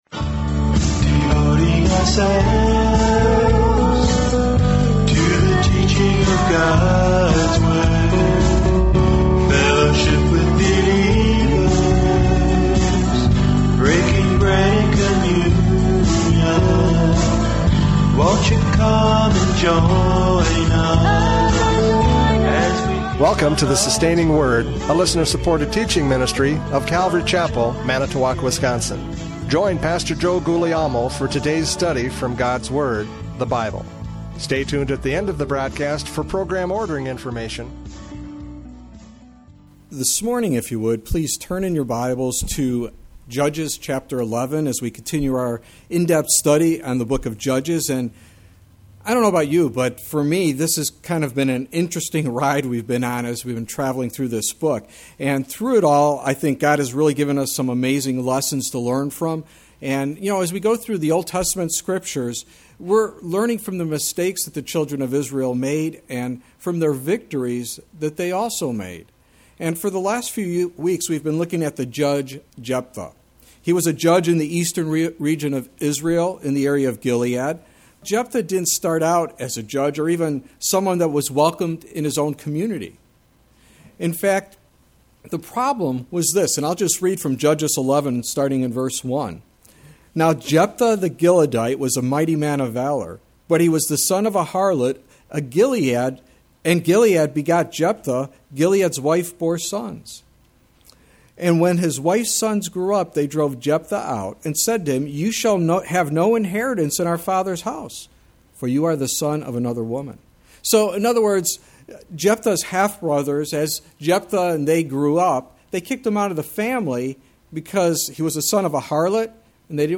Judges 11:29-40 Service Type: Radio Programs « Judges 11:12-28 Jephthah Judges!